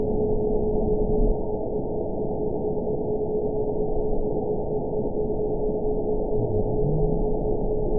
event 922324 date 12/29/24 time 23:37:58 GMT (11 months ago) score 8.81 location TSS-AB04 detected by nrw target species NRW annotations +NRW Spectrogram: Frequency (kHz) vs. Time (s) audio not available .wav